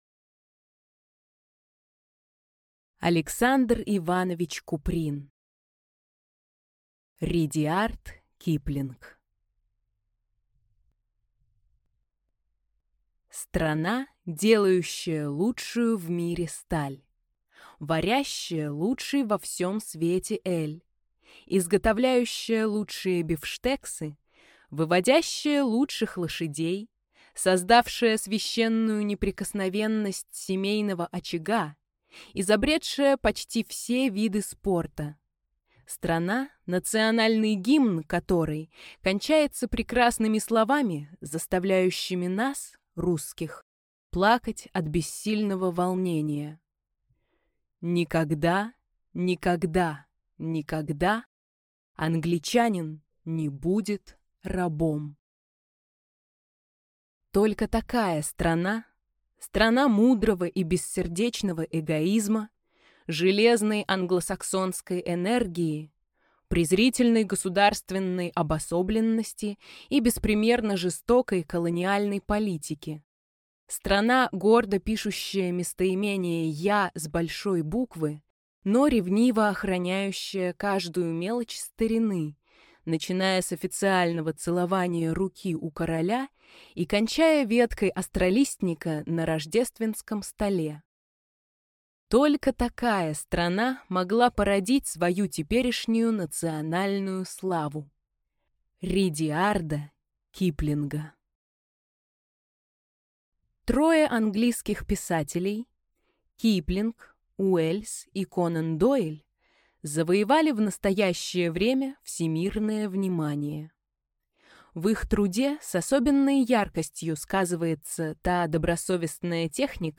Aудиокнига Редиард Киплинг Автор Александр Куприн Читает аудиокнигу Дарья Мороз.